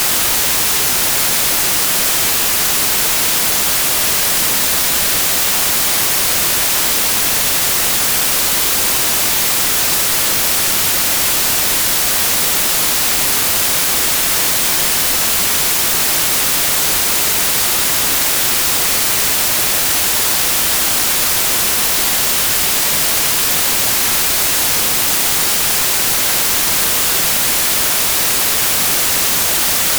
audiocheck-net_white_192k_-3dbfs.wav